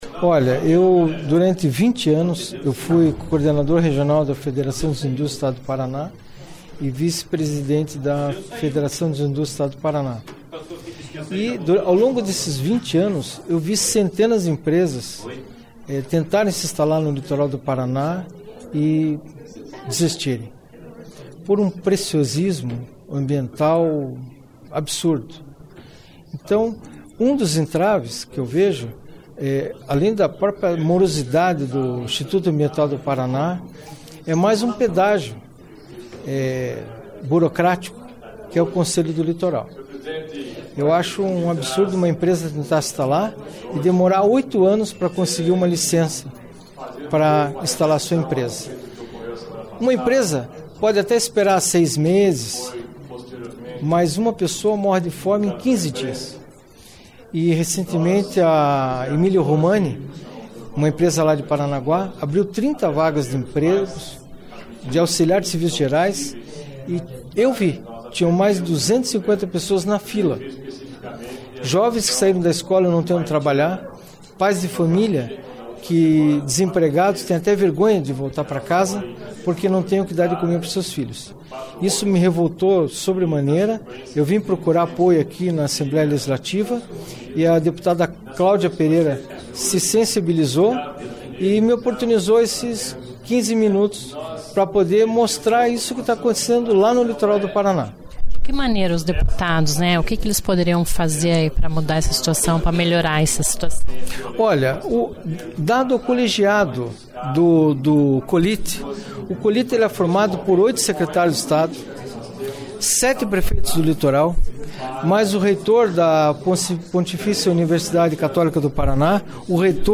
Ouça a entrevista com o empresário e em seguida, com a deputada. (Sonoras)